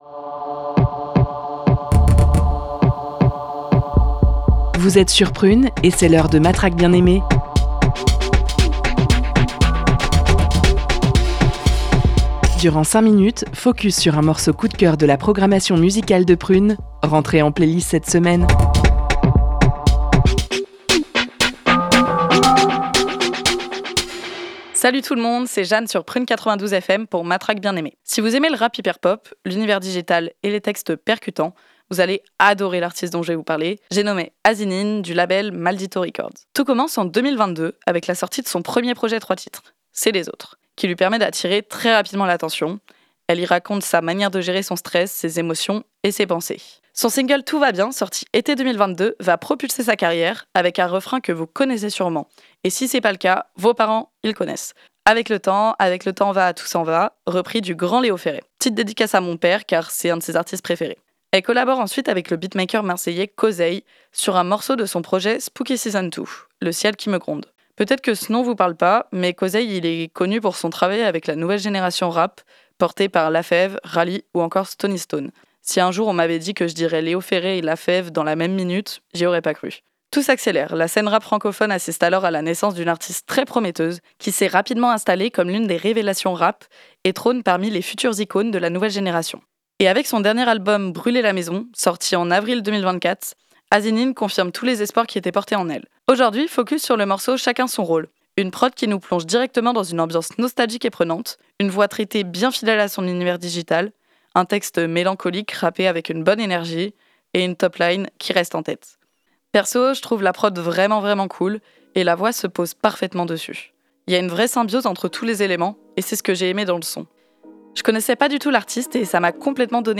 une rappeuse promise à une grande carrière